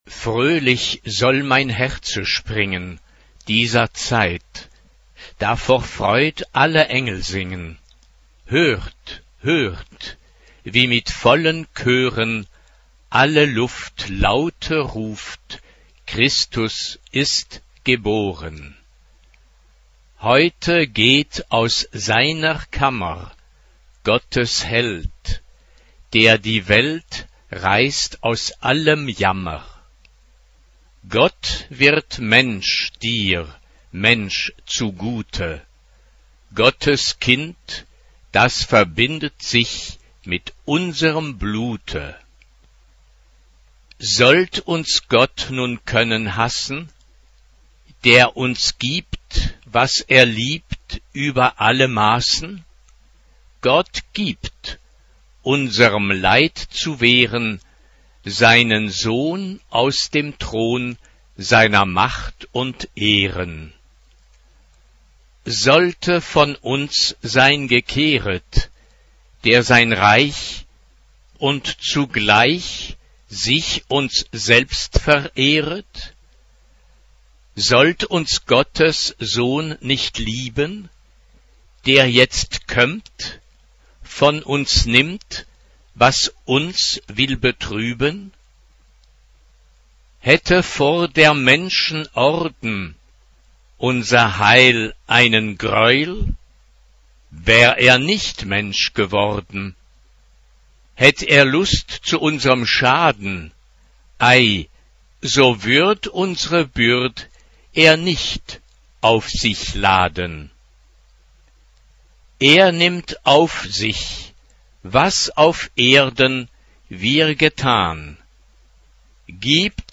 Sacred ; Chorale
SA (2 women voices )
Keyboard (1 instrumental part(s))
Keyboard (1) ; Melody instrument (optional)
Tonality: F major